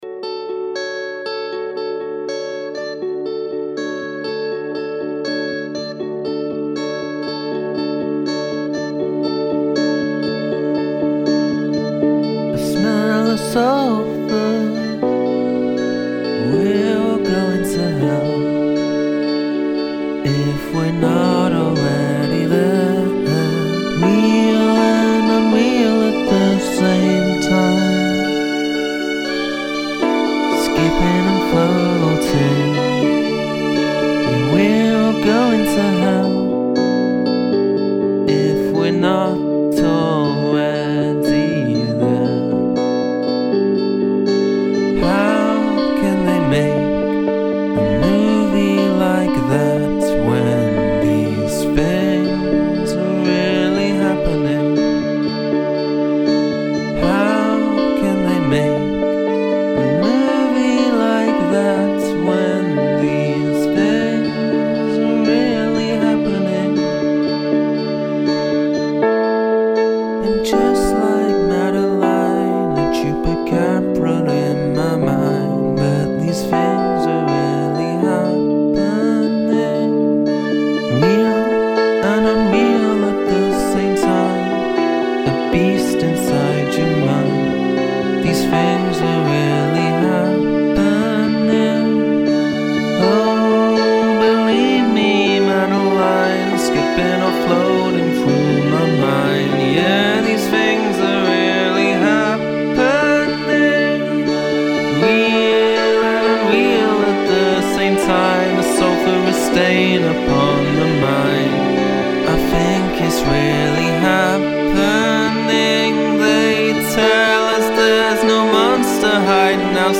Use exactly two instruments (plus vocals) to create your song.
I really like the sonic palette here. Excellent mood and textures. I will take your word for it that there are only two instruments, but at various points, it kind of sounded like you had guitar + electric piano + strings going on instead of just two instruments.